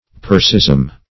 Persism \Per"sism\, n.